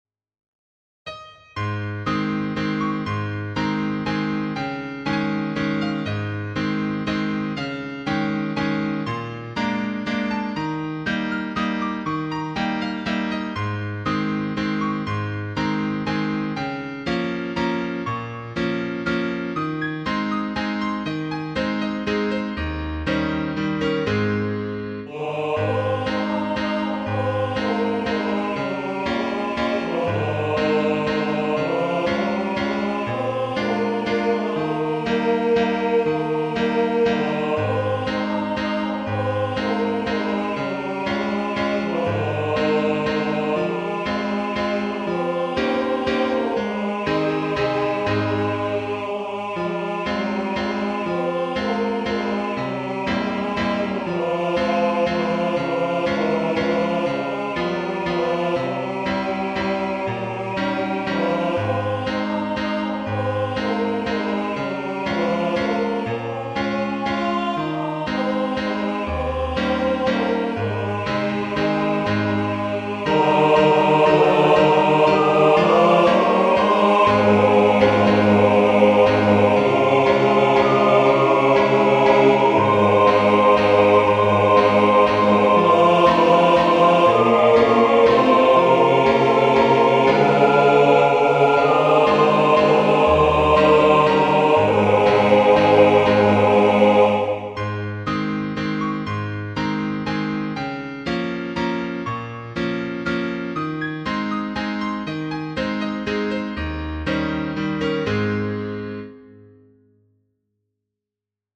This song, “There’s Love in Her Bright Blue Eye”, is a sentimental love song structured as a tenor (?) solo on the verses followed by an SATB chorus.
Click here to play or download an mp3 audio recording of “There’s Love in Her Bright Blue Eye” (will sound a bit mechanical, as this is merely scanned from old sheet music, then using synth piano and vocal lines)